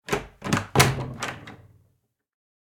dooropen2.ogg